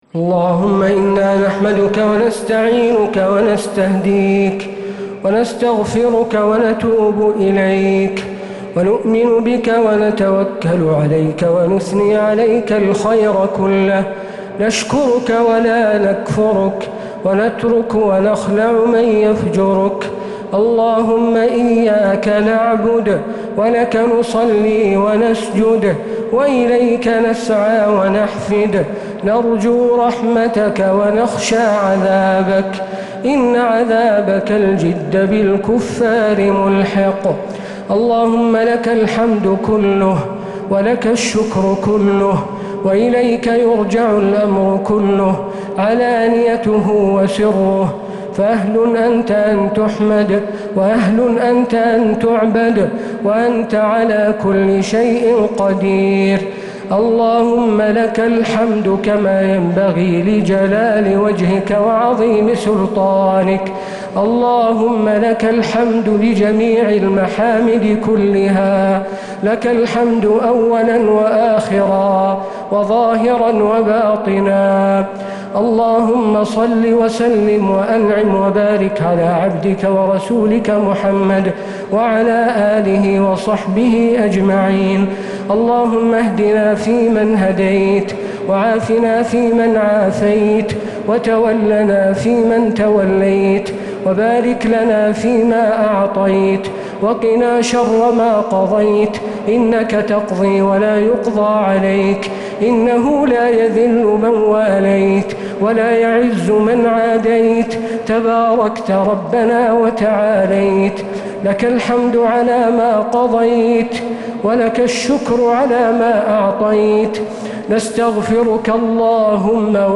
دعاء القنوت ليلة 2 رمضان 1446هـ | Dua 2nd night Ramadan 1446H > تراويح الحرم النبوي عام 1446 🕌 > التراويح - تلاوات الحرمين